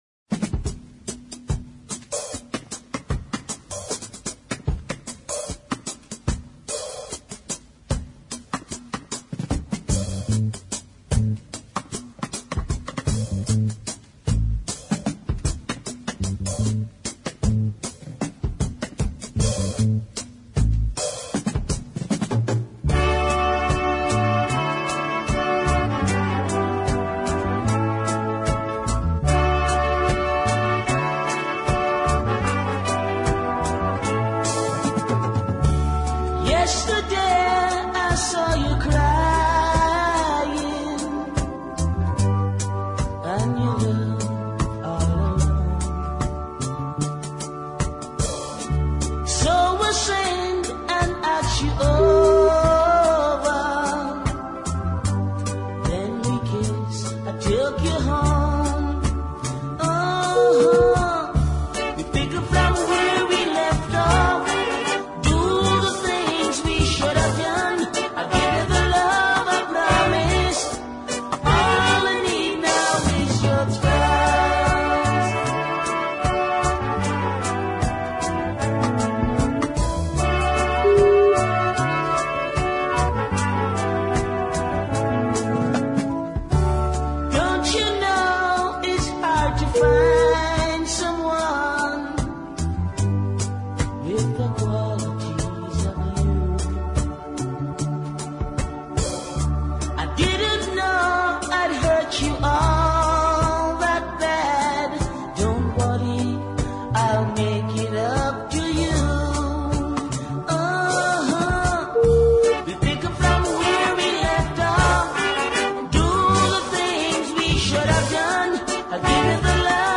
Caribbean Deep Soul